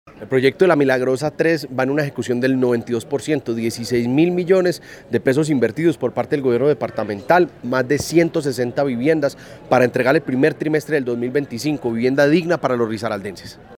En una jornada de socialización realizada en la Casa de la Cultura de La Virginia, funcionarios de la Empresa de Desarrollo Urbano y Rural de Risaralda (EDUR) presentaron los avances de la obra a los beneficiarios del proyecto de vivienda Bosques de La Milagrosa III.
JUAN-DIEGO-PATINO-GOBERNADOR-1.mp3